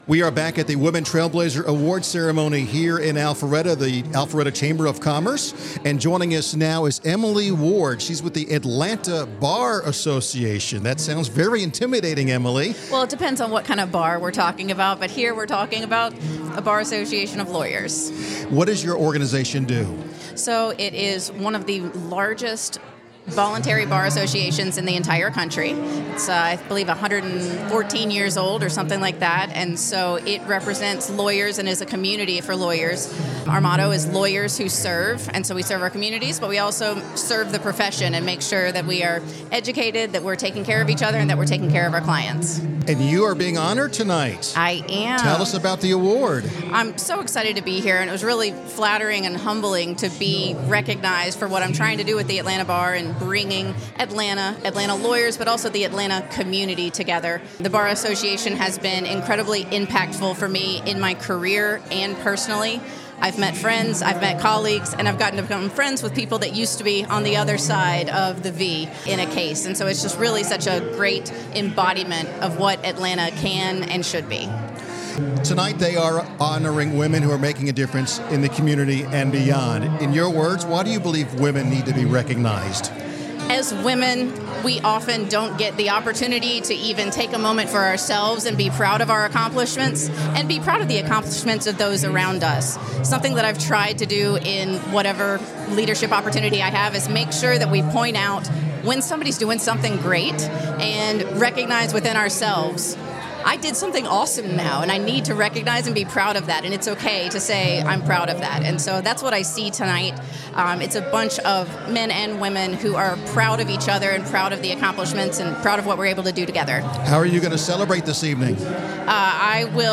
Business RadioX partnered with Riptide, LLC to interview several of the winners and other leaders attending the prestigious event.